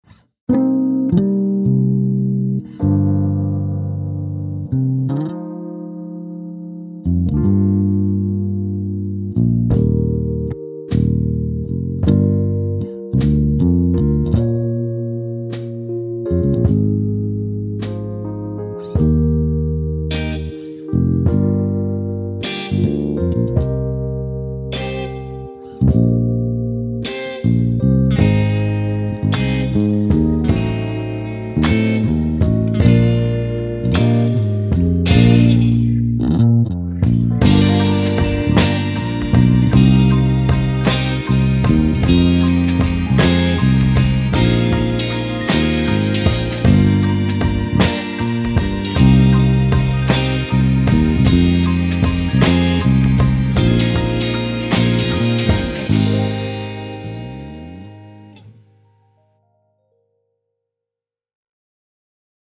柔滑而优雅的电贝斯
优秀的70年代DISCO及FUNK风格贝斯，包含四种音箱设置采样
Fat 适用于摇滚以及流行风格的音乐制作，音色全面而紧实。
Heavy 适用于重摇滚风格的音乐制作，音色锋锐而强硬。
Crunchy 适用于摇滚风格的音乐制作，具有强大而野性的失真音色。
Pop 适用于R&B以及流行风格的音乐制作，音色肥厚而干脆。
声音类别: 电贝斯